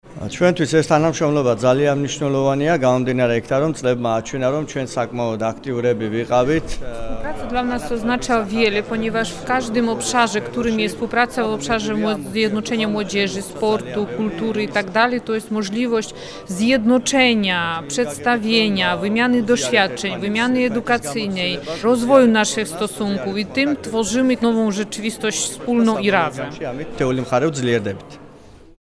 W sali Sejmiku Województwa Dolnośląskiego we Wrocławiu odbyło się trójstronne spotkanie prezydiów parlamentów regionalnych Dolnego Śląska, Autonomicznej Republiki Adżarii oraz Kraju Związkowego Brandenburgii.